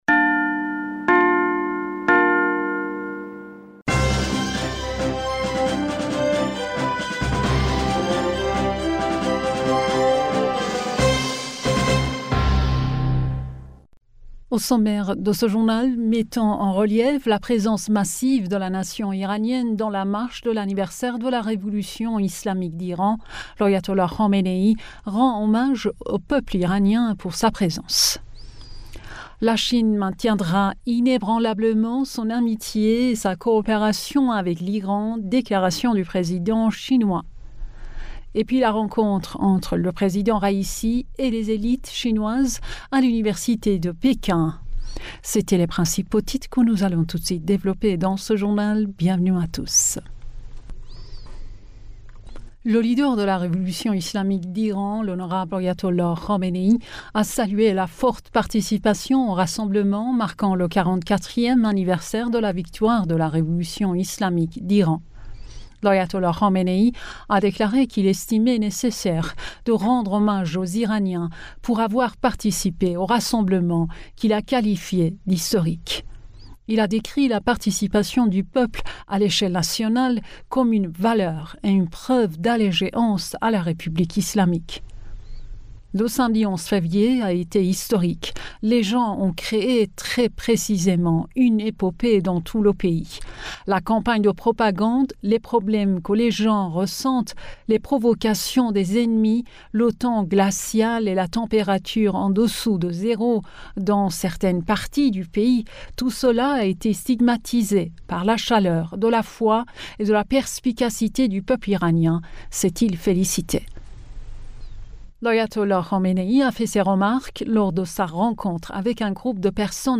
Bulletin d'information du 15 Février